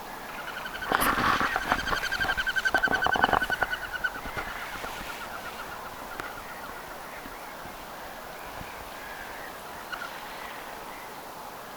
tässä kuuluu telkän laskeutuessa tuollainen yksittäinen ääni
lopussa_siivista_tuleva_aani_kun_koiras_telkka_laskeutui.mp3